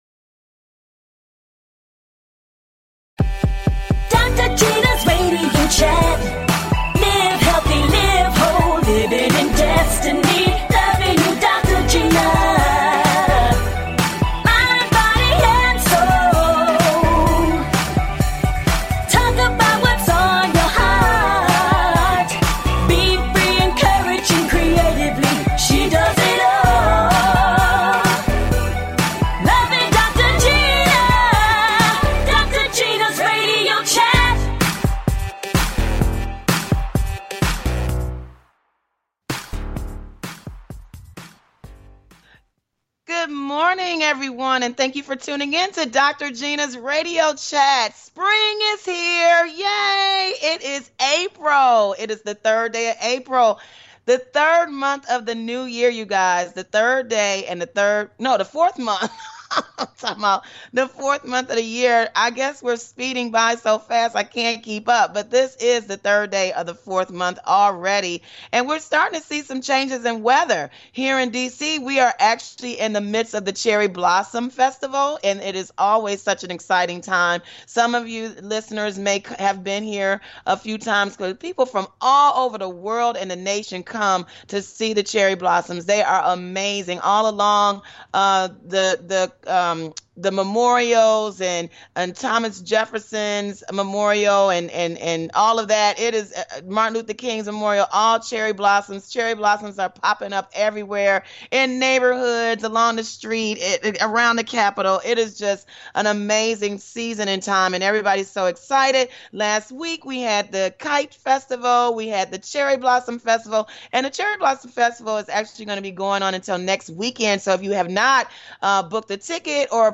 Guests, Comedian